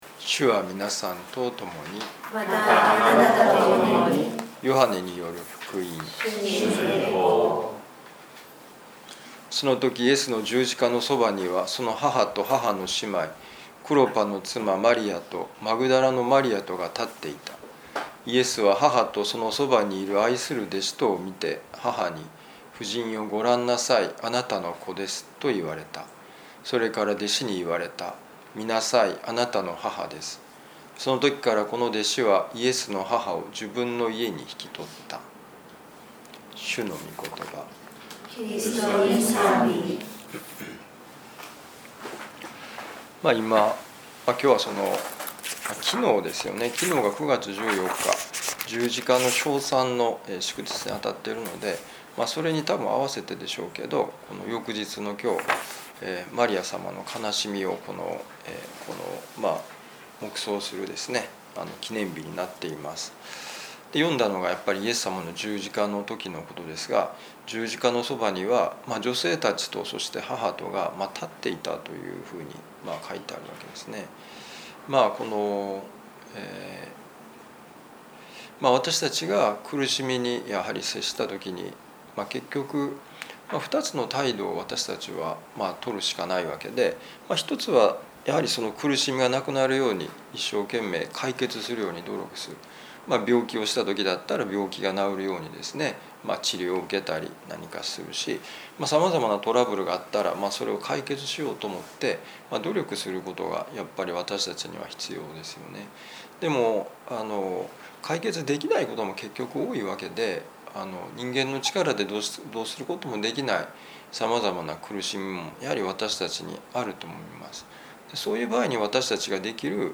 ヨハネ福音書19章25-27節「十字架の下に立つ信仰」2025年9月15日年悲しみの聖母 いやしのミサ旅路の里